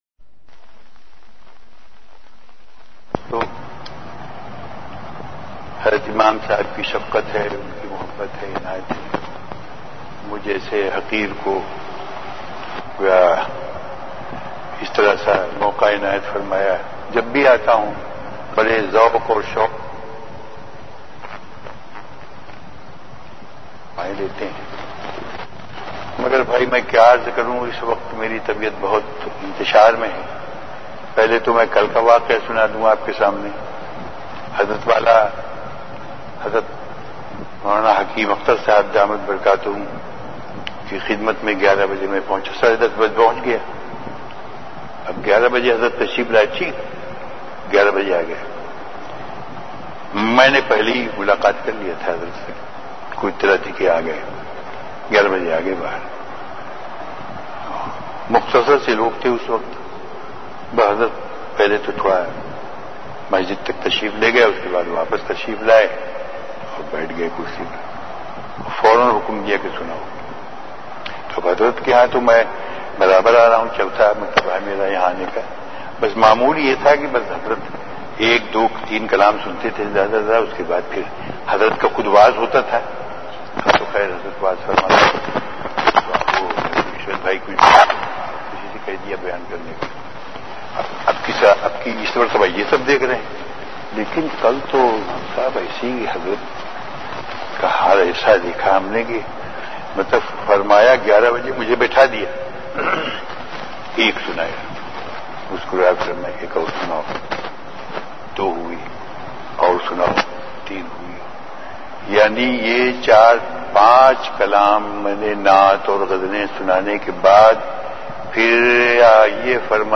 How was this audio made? Delivered at Home.